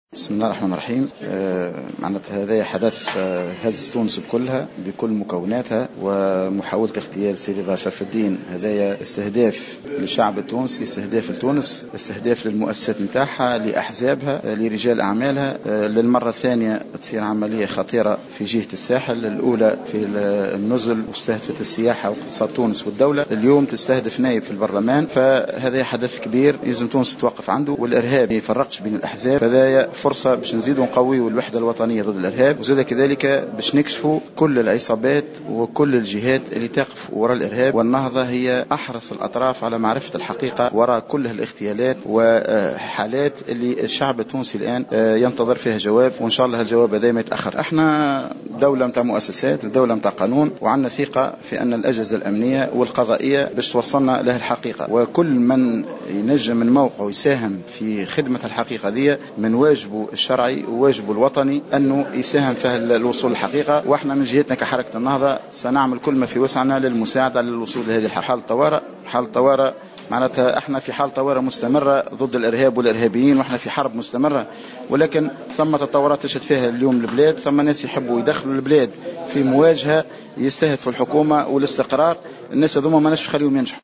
أكد القيادي في حركة النهضة عبد الكريم الهاروني في تصريح اعلامي على هامش الندوة الصحفية التي انعقدت اليوم حول حادثة محاولة اغتيال رضا شرف الدين أن ما حدث اليوم حدث كبير يجب أن تتوقف عنده تونس وهو فرصة لتقوية الوحدة الوطنية ضد الإرهاب والكشف عن العصابات والجهات التي تقف وراء الإرهاب وفق قوله.